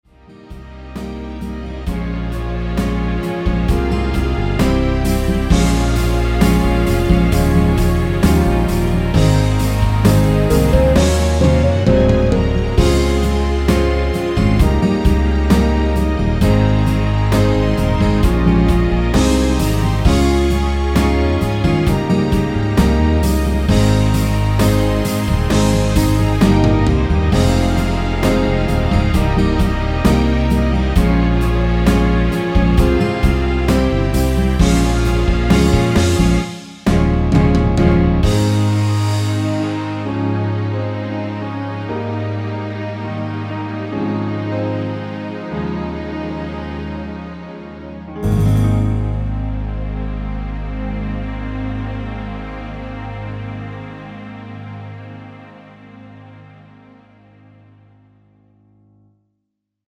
원키에서(-2)내린 (짧은편곡) MR입니다.
F#
앞부분30초, 뒷부분30초씩 편집해서 올려 드리고 있습니다.